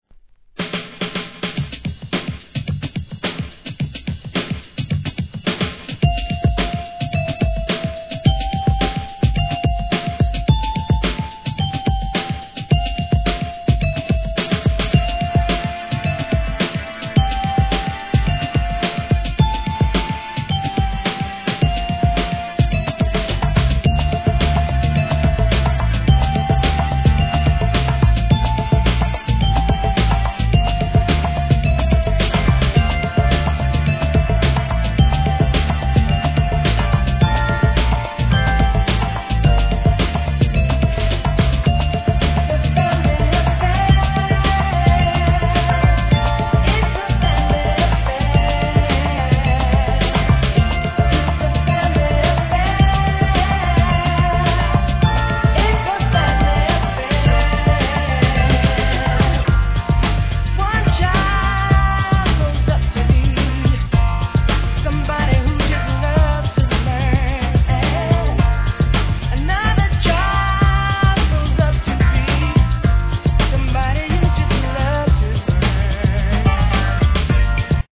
HIP HOP/R&B
疾走間あるBEATにGROOVE感溢れる好カヴァー!!